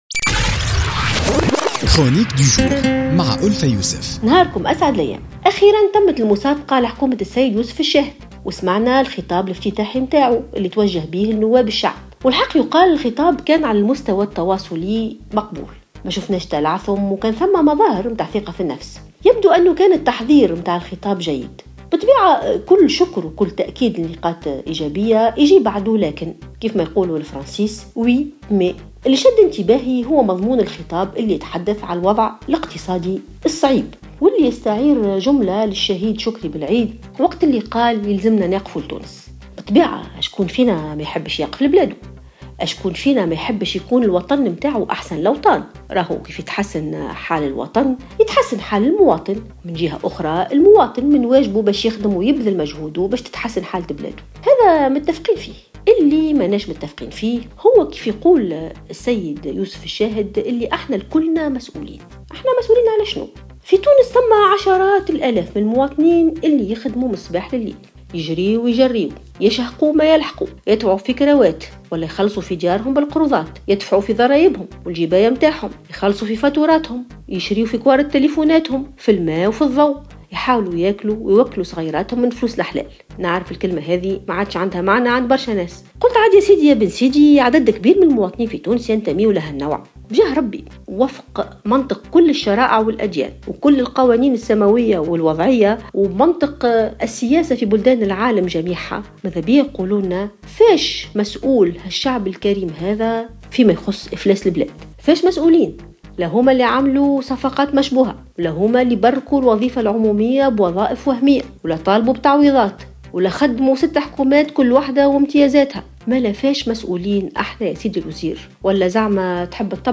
اعتبرت الكاتبة ألفة يوسف في افتتاحيتها اليوم الثلاثاء بـ "الجوهرة اف أم" أن خطاب رئيس الحكومة الجديد يوسف الشاهد كان مقبولا في جلسة منح الثقة.